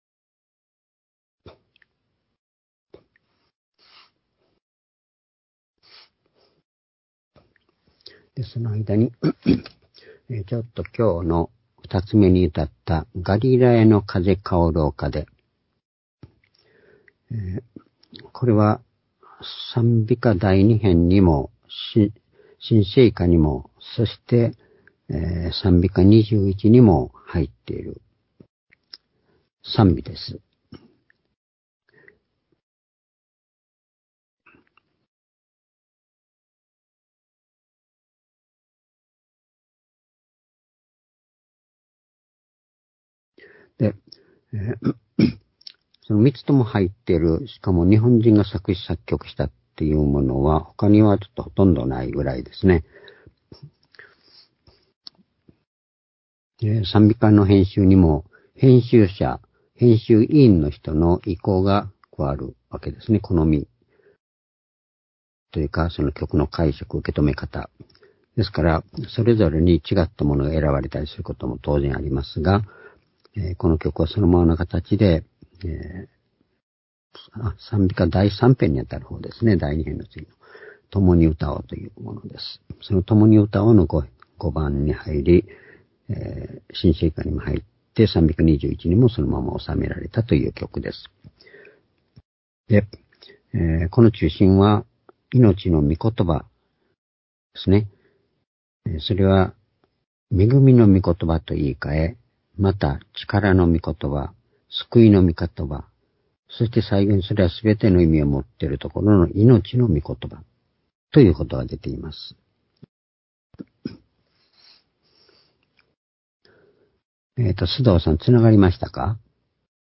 「主よ我ら 誰に行かん。永遠の命の言は、汝にあり」-ヨハネ６章６６節～７１節-２０２２年10月30日（主日礼拝）